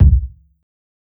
KICK_CALF.wav